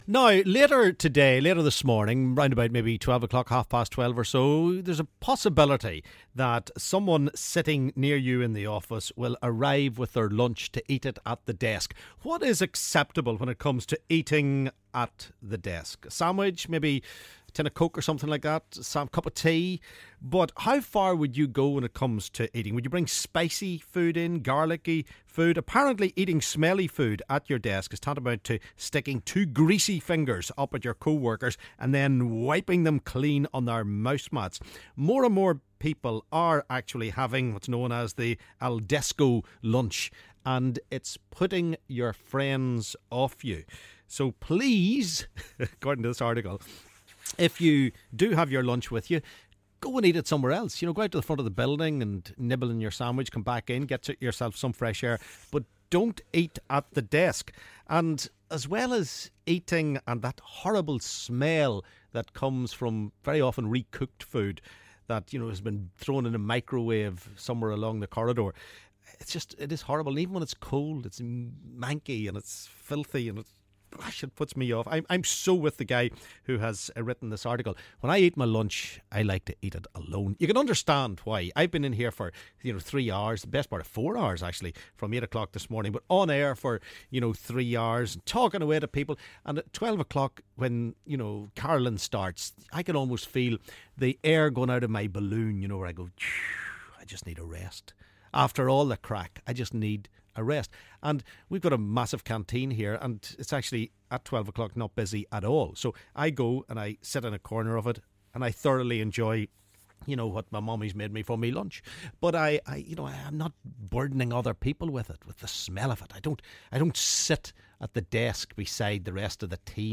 LISTEN: Our callers are getting annoyed about noisey eaters at work!